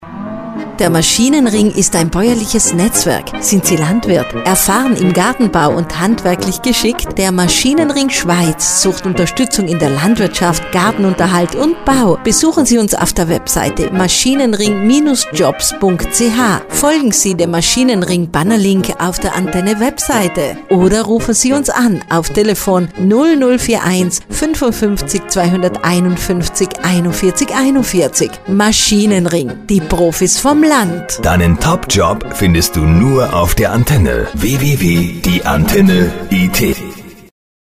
Trailer_Maschinenring_Jobs.mp3